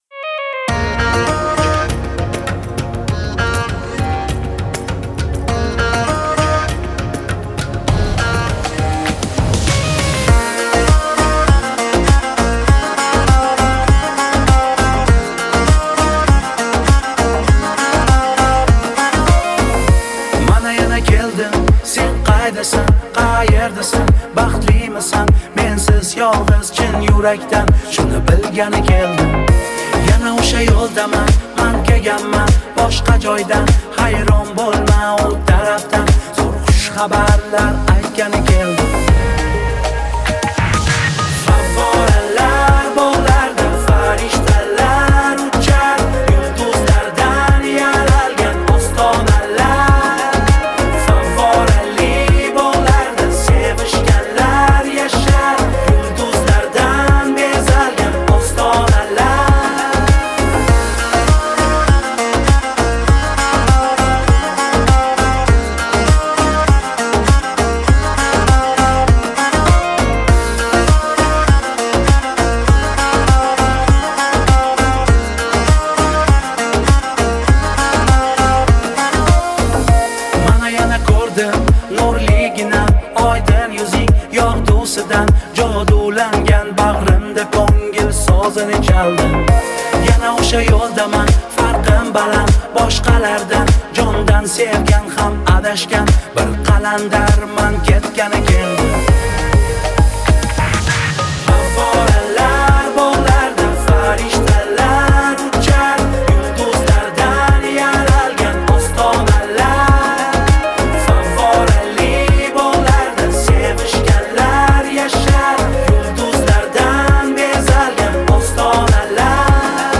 Трек размещён в разделе Узбекская музыка / Поп / 2022.